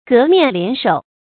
革面敛手 gé miān liǎn shǒu
革面敛手发音
成语注音ㄍㄜˊ ㄇㄧㄢˋ ㄌㄧㄢˇ ㄕㄡˇ